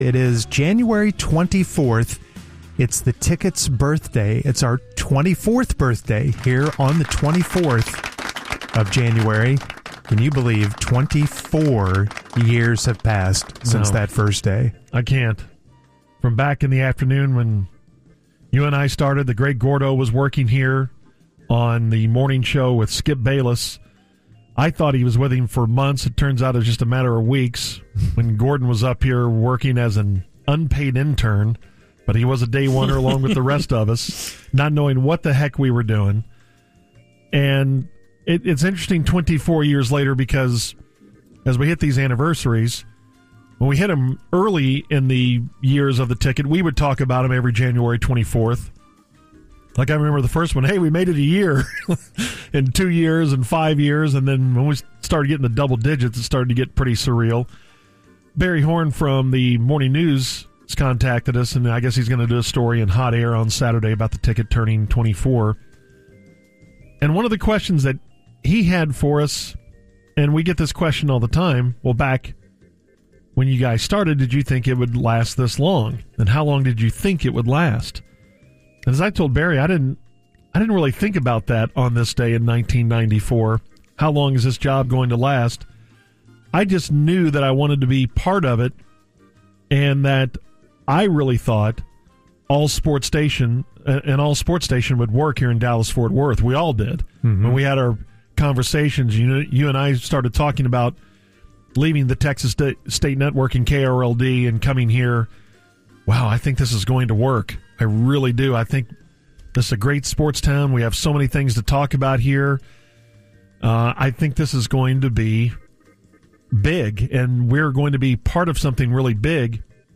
I gathered up the segments from each show discussing Ticket history for this nice one hour compilation.